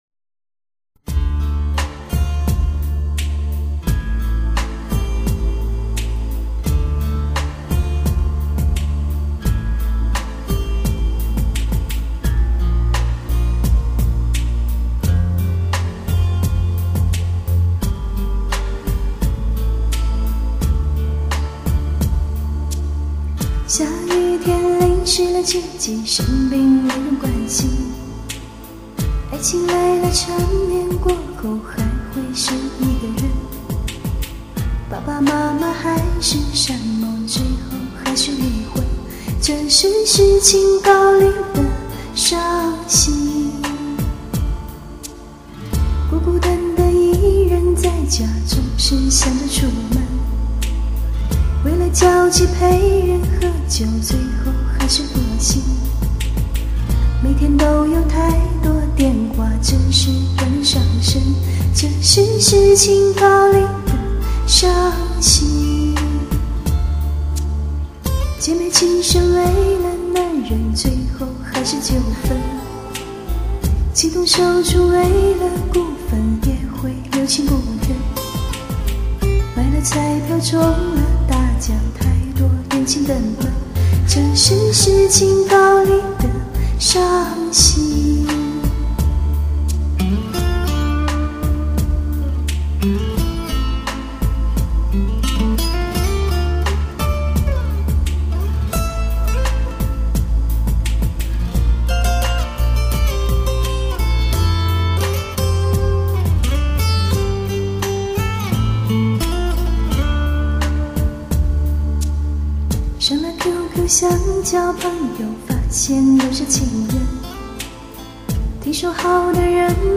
感冒版的,,大家凑合着听下....